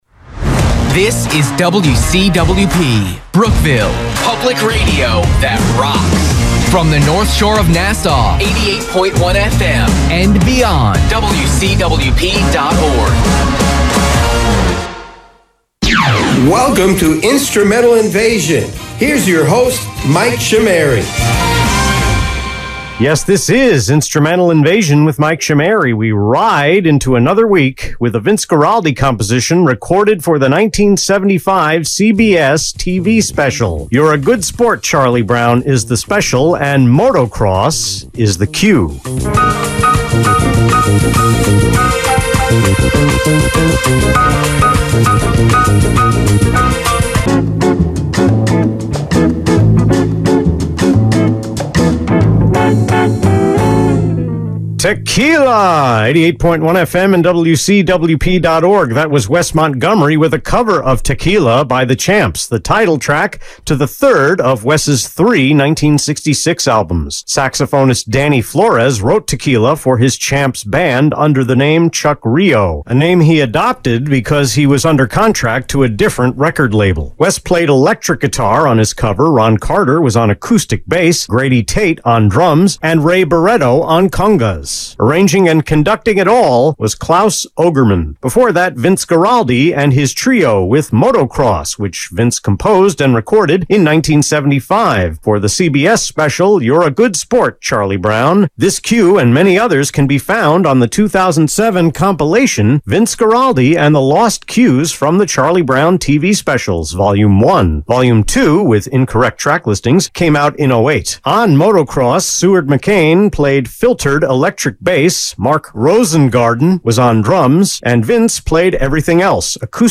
The April 27 Instrumental Invasion on WCWP was recorded on March 8 (two segments) and 9 (four segments).
The second and third talk breaks of the first segment were speed compressed, one more talk break than last February 3.
The third and fourth segments had the opposite problem, which meant padding with extra liners and not having songs start underneath the talk-up.